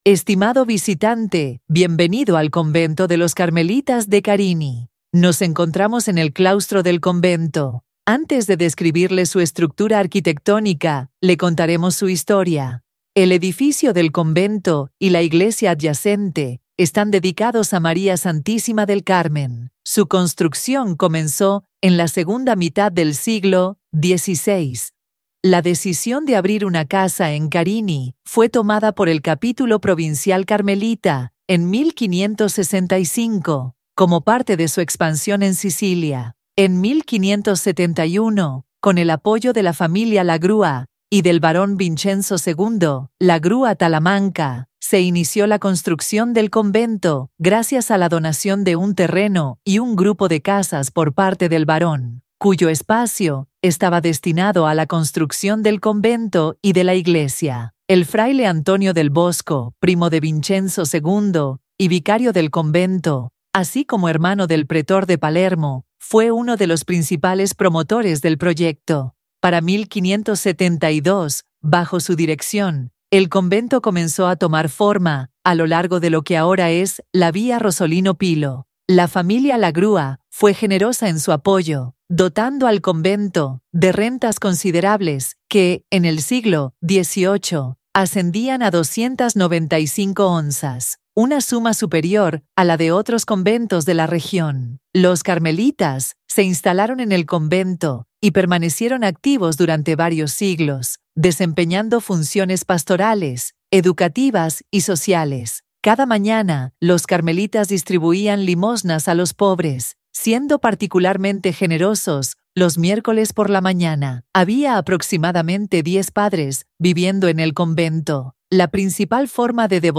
Audio Guide